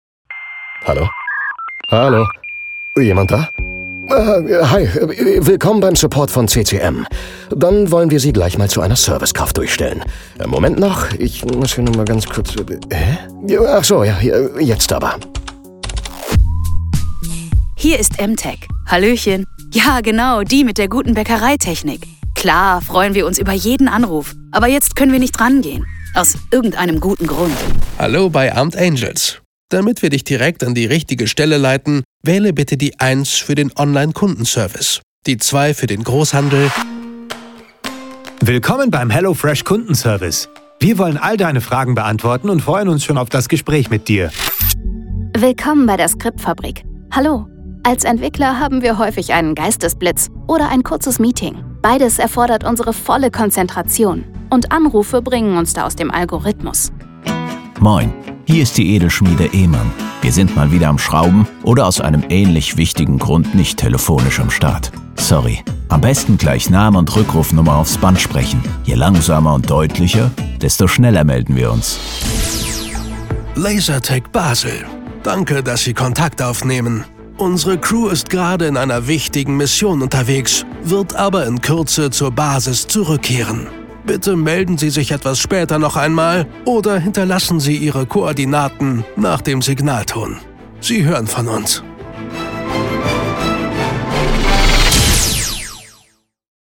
Frisch, dynamisch und nahbar